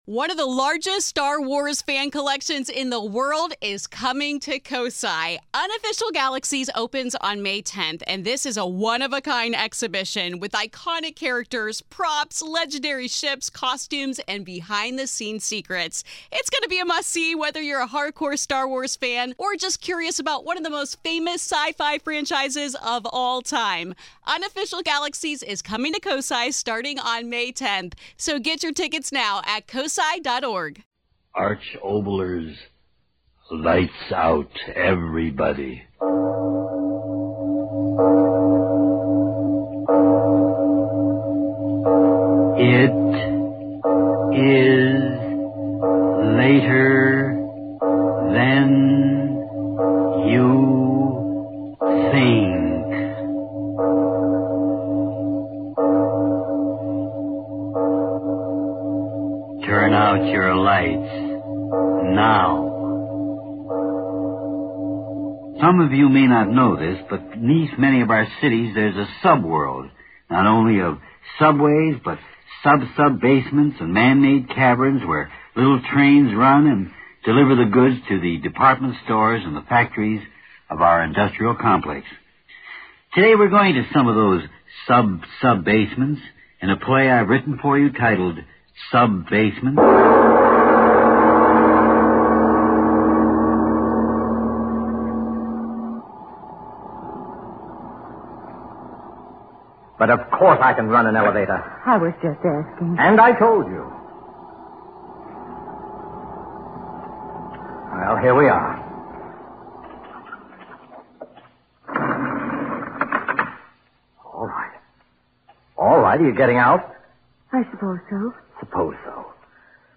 On this week's episode of the Old Time Radiocast we present you with two stories from the classic radio program Lights Out.